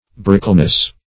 Brickleness \Bric"kle*ness\, n.